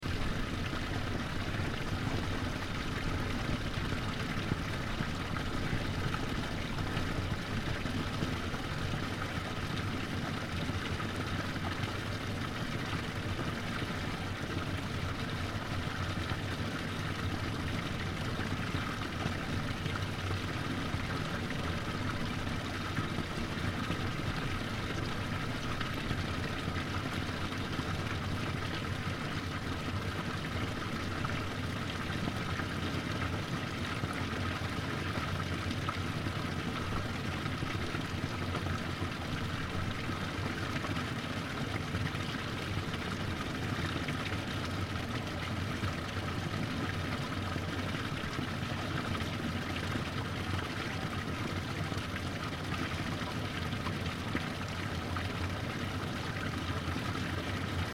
دانلود آهنگ آب 37 از افکت صوتی طبیعت و محیط
جلوه های صوتی
دانلود صدای آب 37 از ساعد نیوز با لینک مستقیم و کیفیت بالا